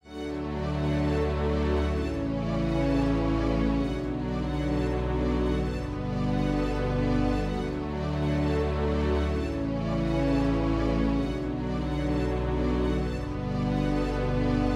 电影主题 " 02259 悲伤的电影交响乐循环
描述：循环，可作为使用VST乐器创作的悲伤或戏剧性场景的背景音乐管弦乐电影类型循环。
Tag: 电影 管弦乐 反思 悲伤 交响乐 主题